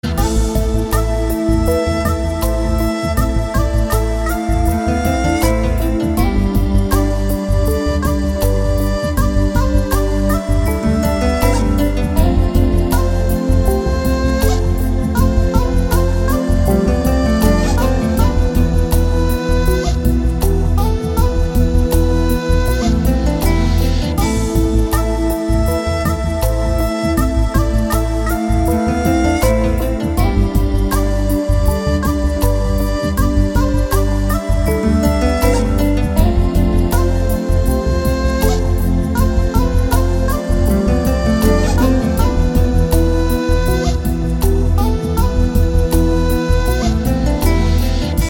• Качество: 256, Stereo
мелодичные
без слов
Флейта
звонкие
relax